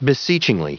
Prononciation du mot beseechingly en anglais (fichier audio)
Prononciation du mot : beseechingly